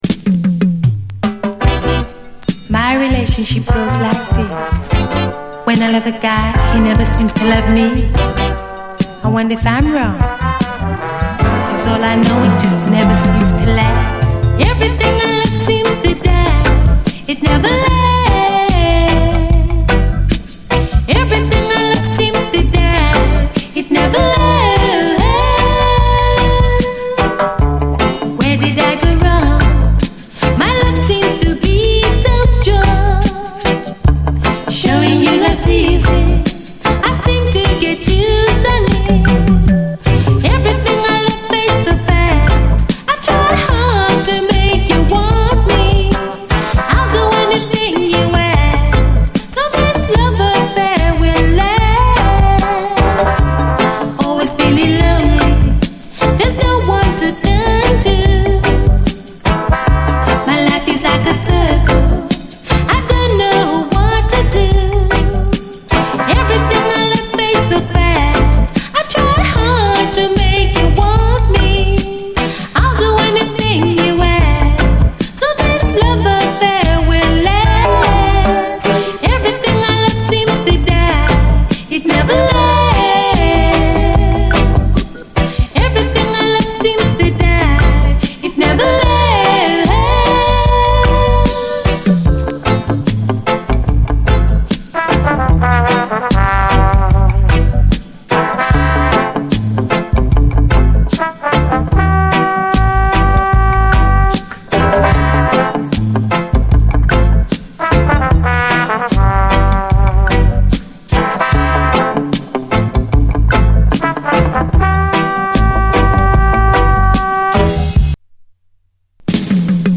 REGGAE
レアなキラーUKラヴァーズ！
甘いイントロの語りからKOされてしまいます！